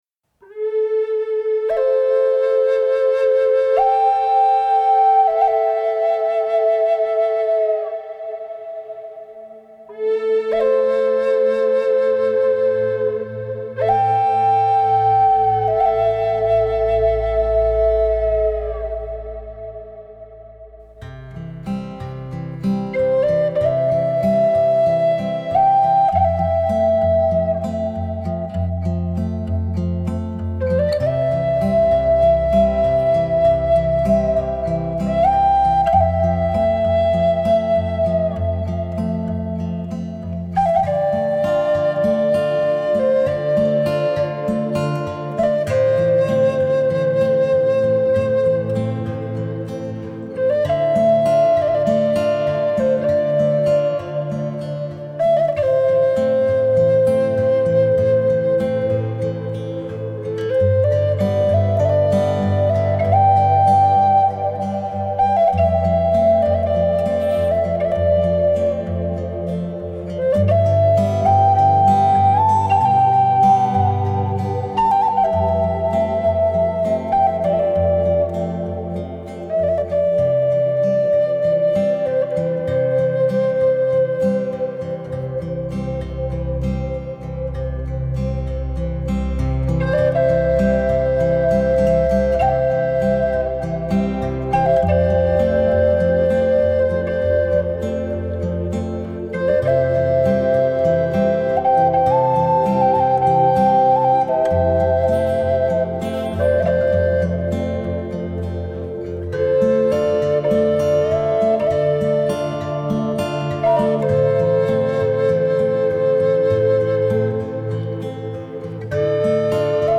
سبک بومی و محلی
موسیقی بی کلام سرخپوستی موسیقی بی کلام فلوت